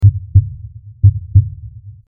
Heartbeat 03
Heartbeat_03.mp3